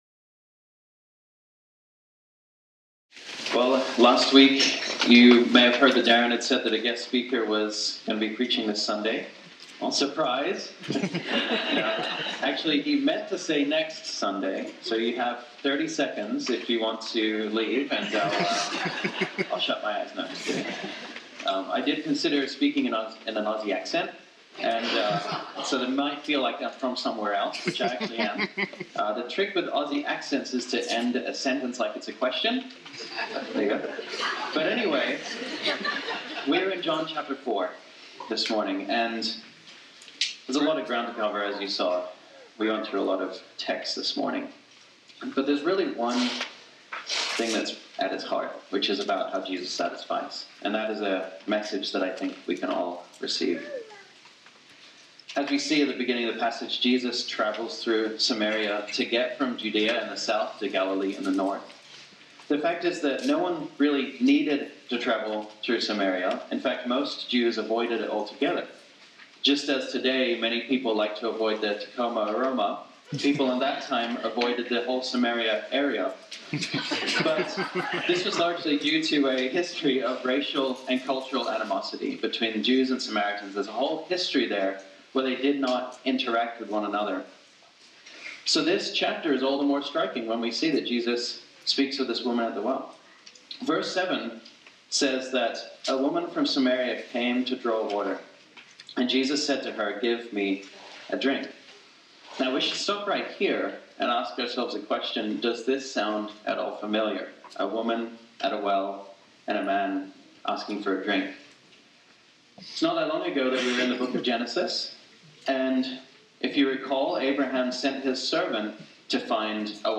This sermon was originally preached on Sunday, October 27, 2019.